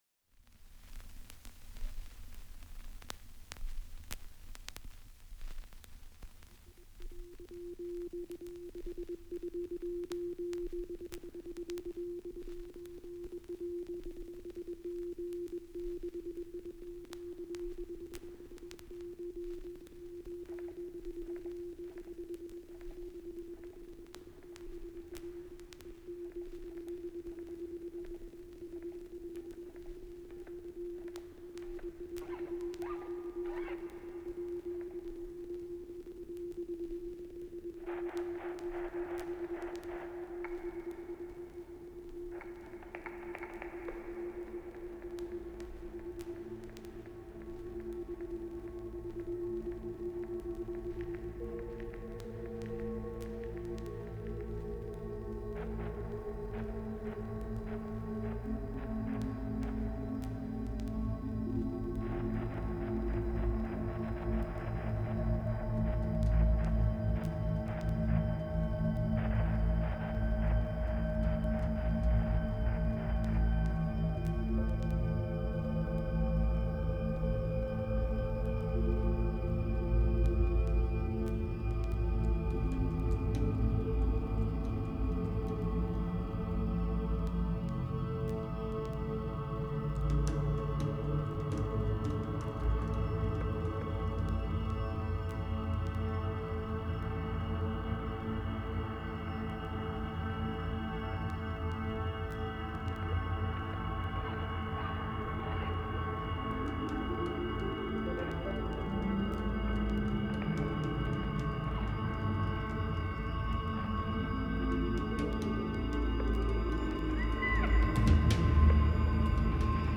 Suivant l’inspiration drone du moment je me suis dit : pourquoi, pas de concert, bourdonner, bruisser, vrombir ou encore bombiller quelque peu ?
Allons, derechef, composer quelques assemblages sonores accompagnés d’une vidéo.